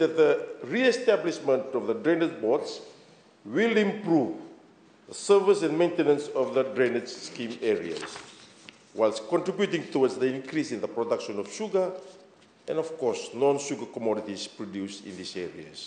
Attorney General Siromi Turaga, in his address in Parliament earlier this week, highlighted the significance of this move to re-establish the drainage board.